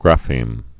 (grăfēm)